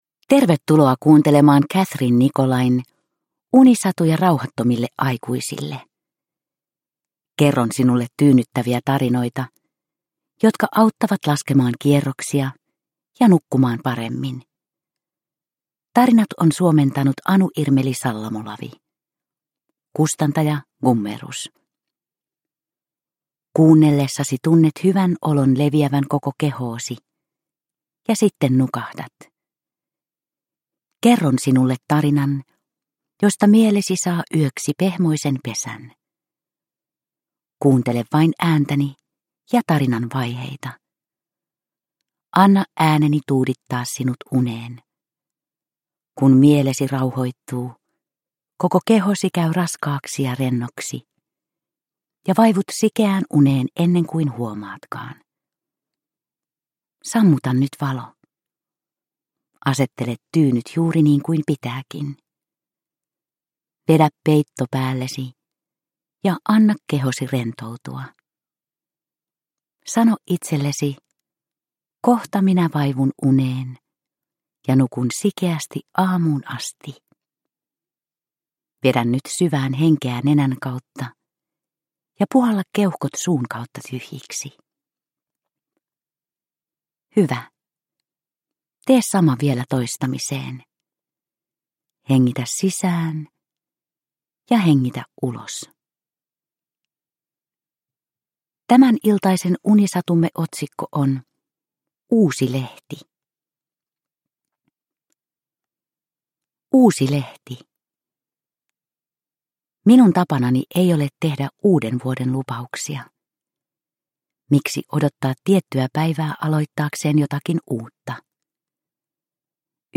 Unisatuja rauhattomille aikuisille 15 - Uusi lehti – Ljudbok – Laddas ner